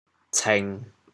“清”字用潮州话怎么说？
cêng1.mp3